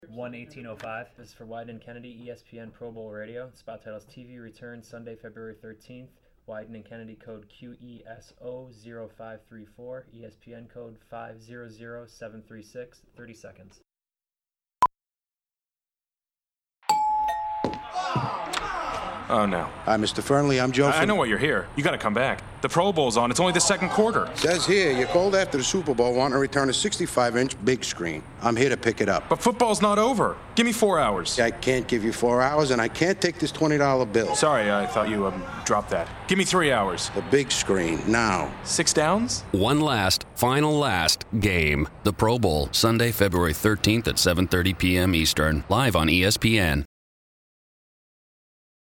Voice Overs
01-espn-radio-tv-return-sunday-feb-13th.mp3